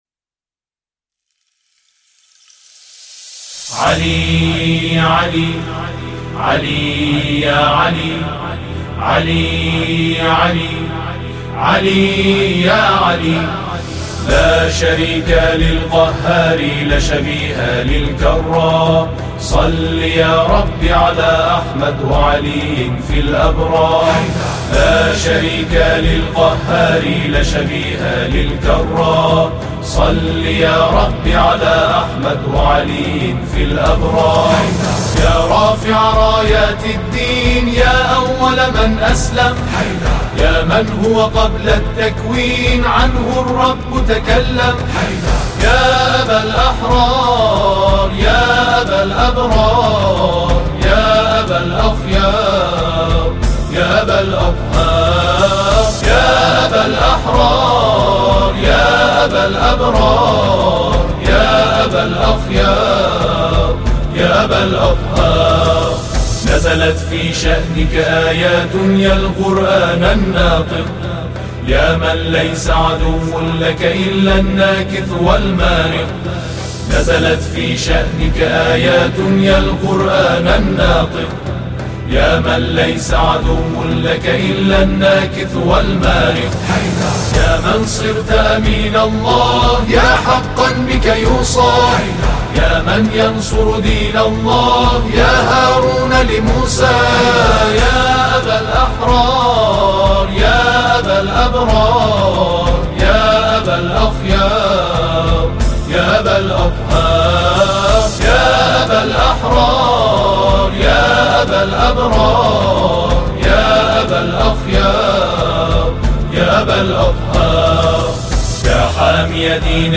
تواشیح امام علی